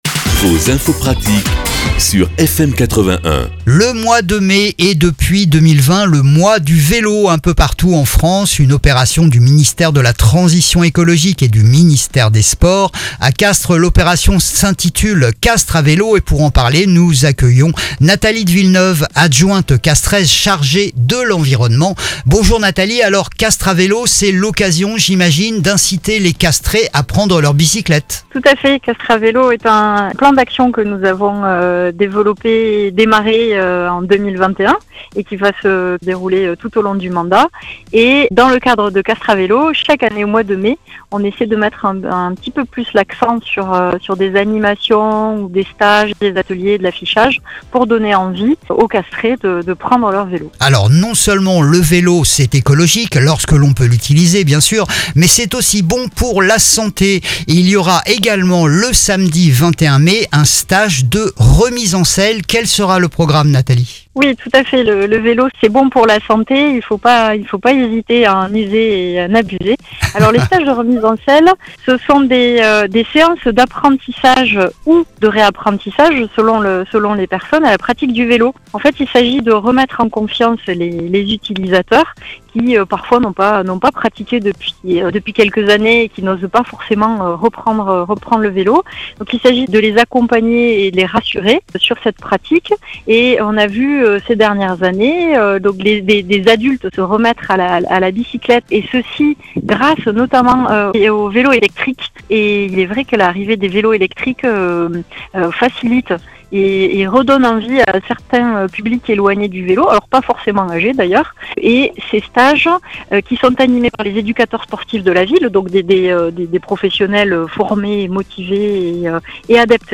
On en parle avec Nathalie de Villeneuve, adjointe à l'environnement, sur FM 81 !